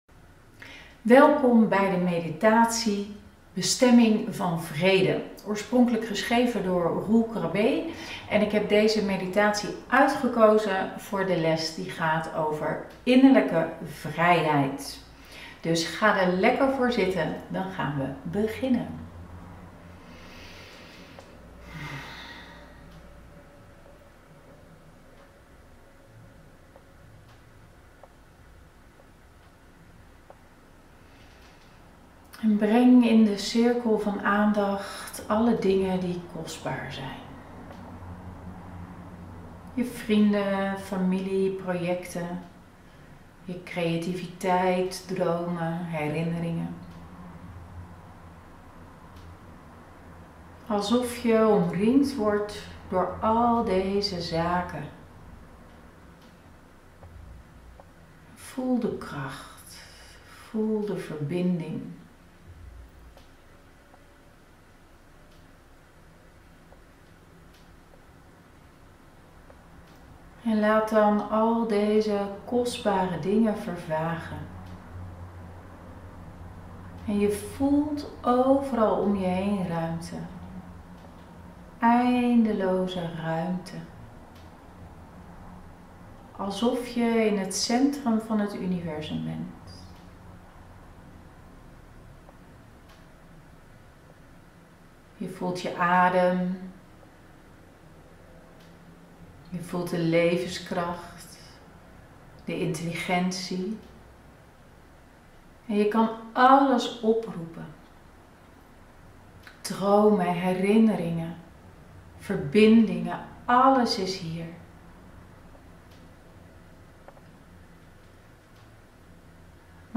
7.1 Meditatie: Bestemming van vrede